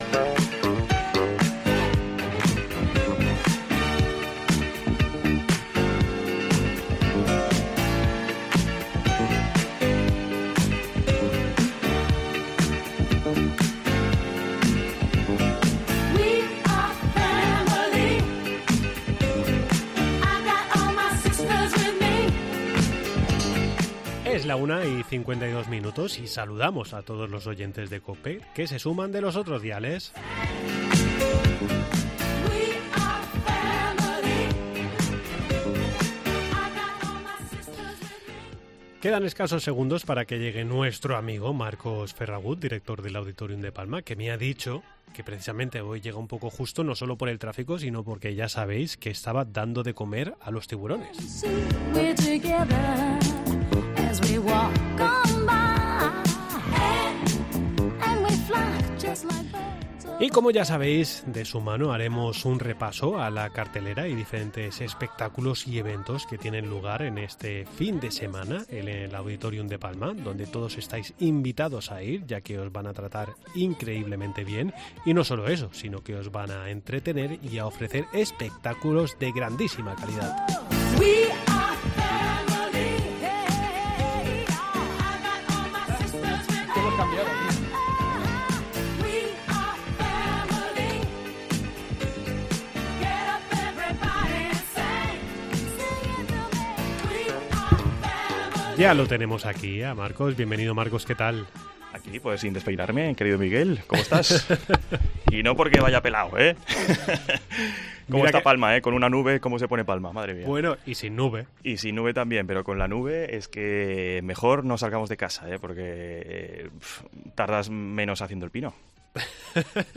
Entrevista en 'La Mañana en COPE Más Mallorca', jueves 01 de junio de 2023.